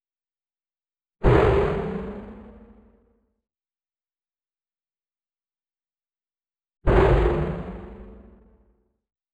Sound-Objects
Electroacoustic
Experimental
Clusters-basse.wav